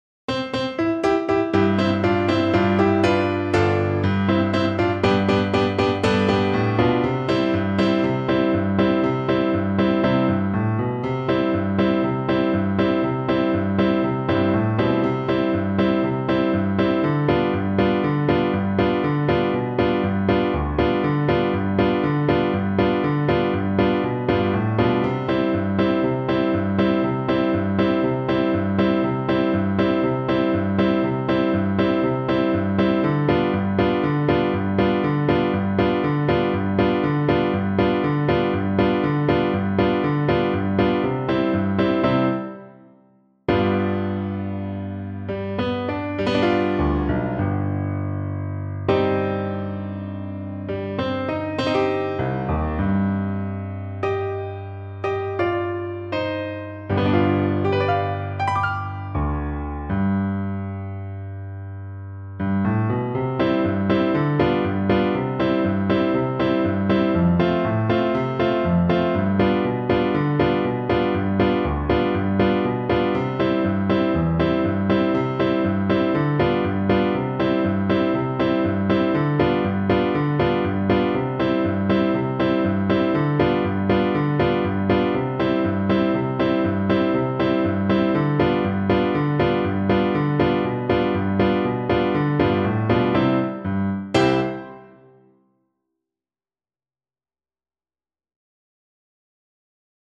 Oboe version
World North America Mexico
C major (Sounding Pitch) (View more C major Music for Oboe )
Mariachi style =c.120
2/4 (View more 2/4 Music)
Traditional (View more Traditional Oboe Music)